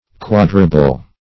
Search Result for " quadrable" : The Collaborative International Dictionary of English v.0.48: Quadrable \Quad"ra*ble\, a. [See Quadrate .]
quadrable.mp3